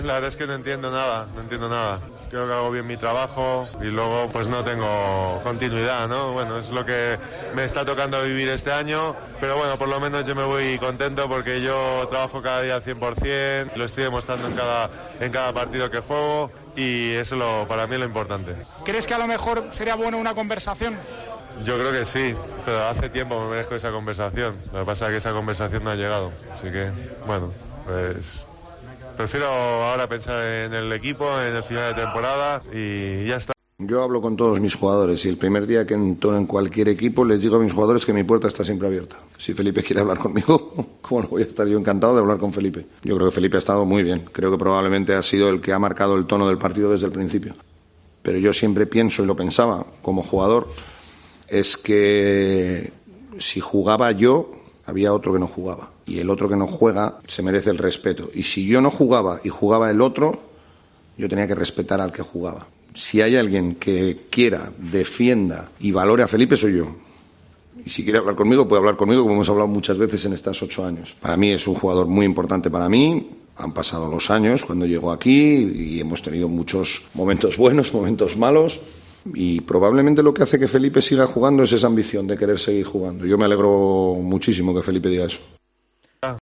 Felipe Reyes habló en Onda Madrid sobre sus pocos minutos de juego y Laso le contestó en la rueda de prensa tras acabar tercero en la Euroliga.